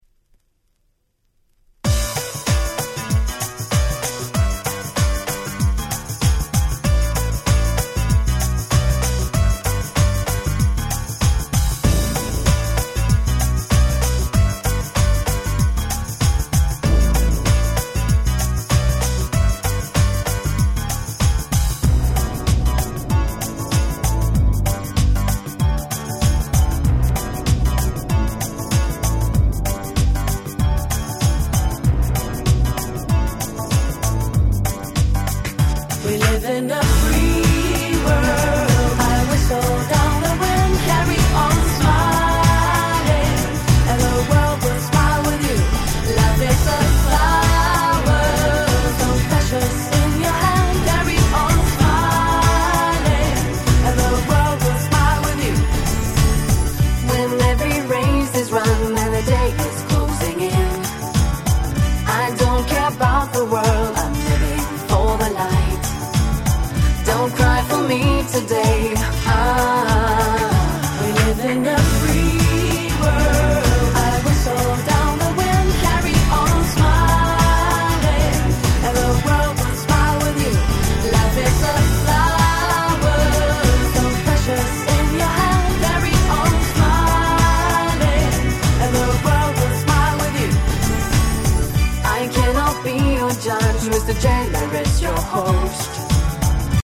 鉄板キャッチーPop R&B♪